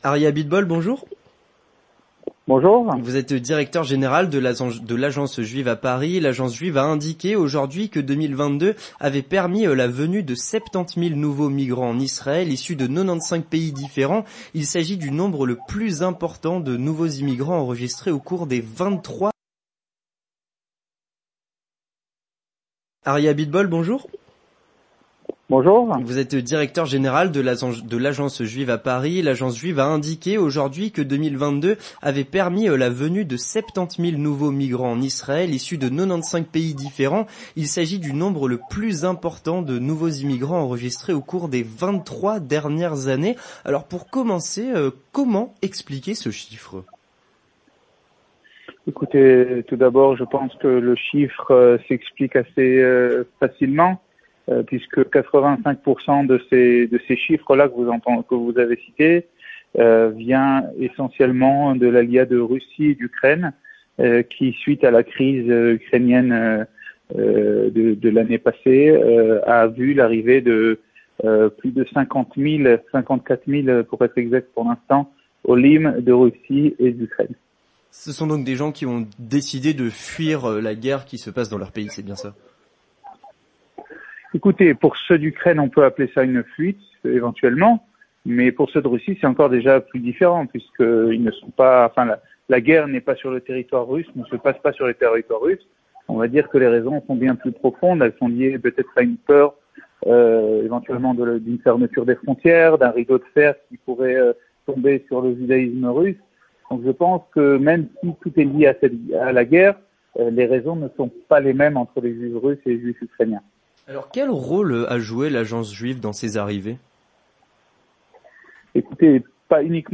L'Entretien du Grand Journal